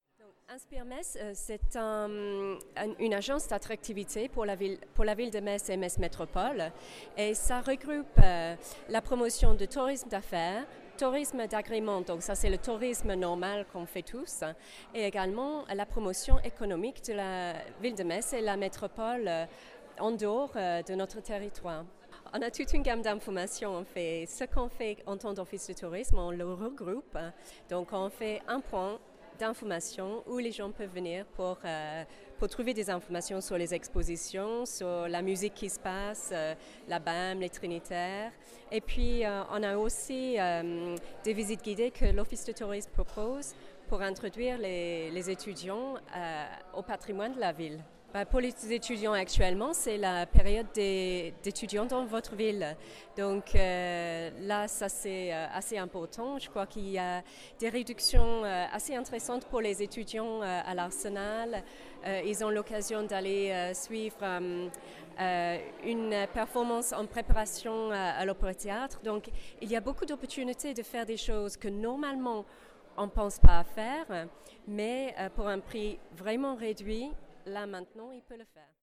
Le vendredi 21 septembre s’est tenu dans le Grand Salon de l’Hôtel de Ville à Metz, le welcome Day des nouveaux étudiants internationaux.
itw-office-tourisme-1.mp3